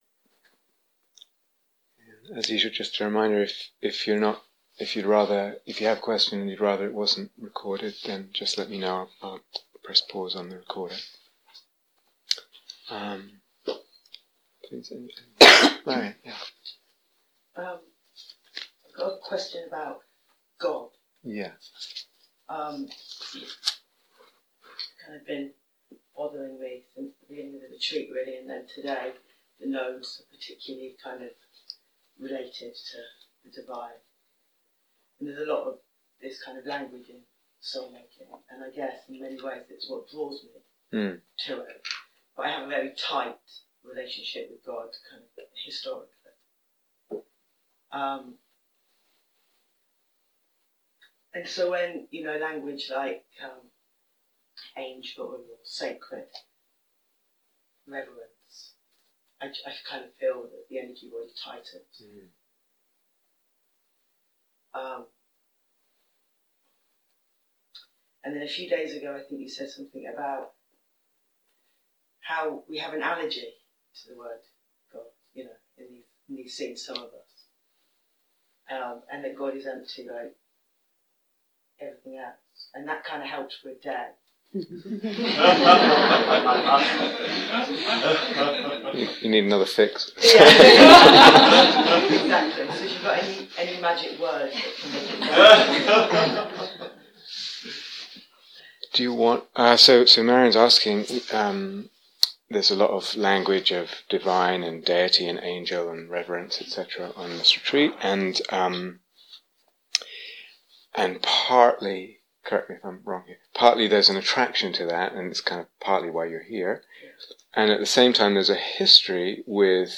Soulmaking Rivers (Q & A)